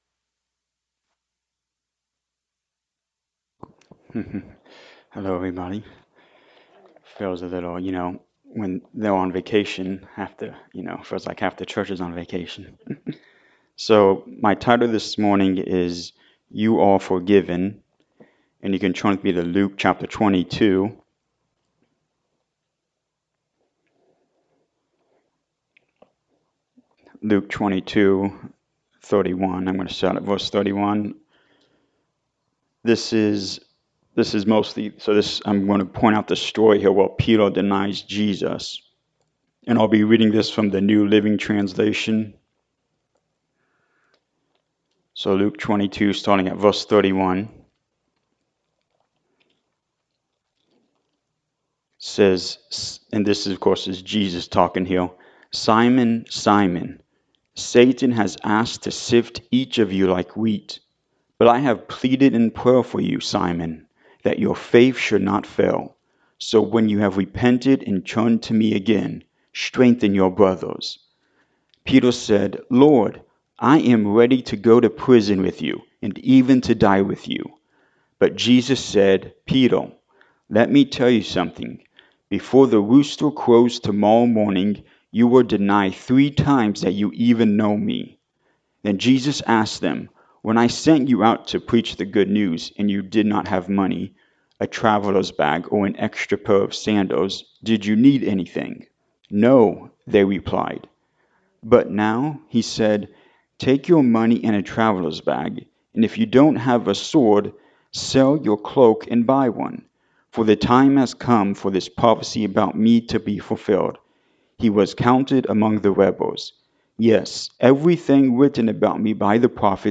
Luke 22:31-71 Service Type: Sunday Morning Service Jesus knows you.